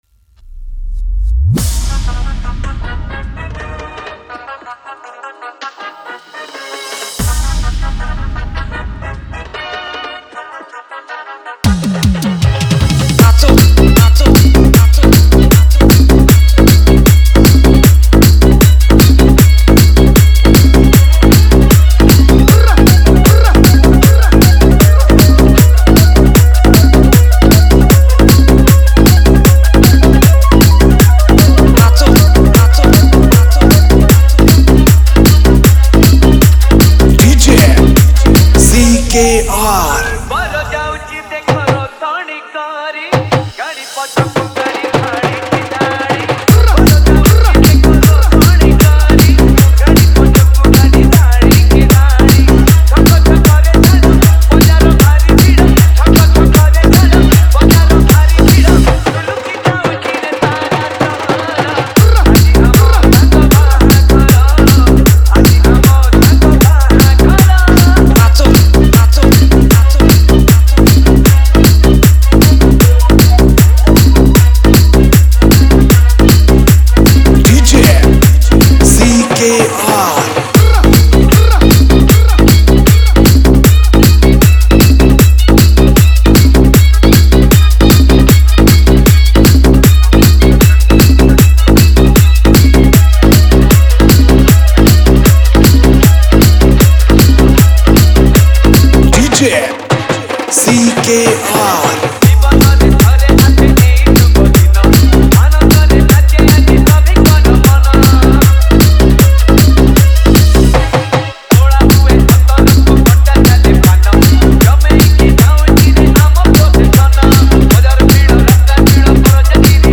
Category: Holi Special Odia Dj Remix Songs